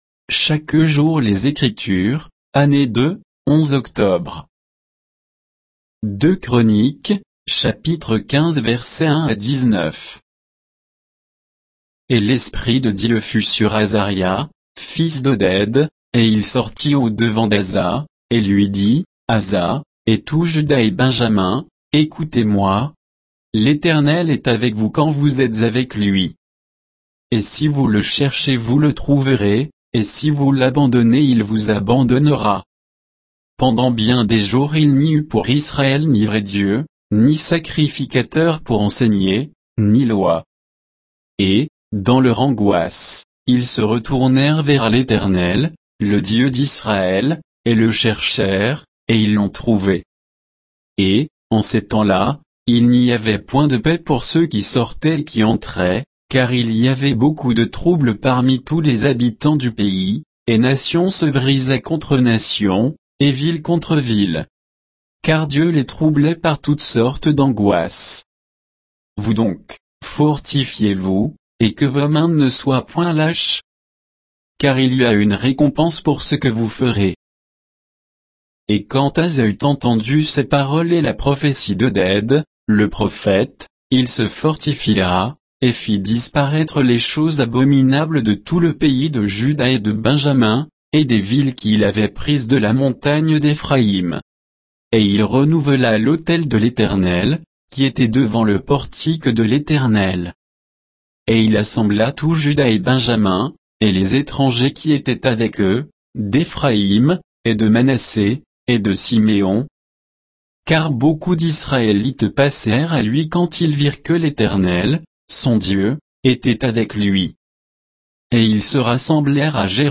Méditation quoditienne de Chaque jour les Écritures sur 2 Chroniques 15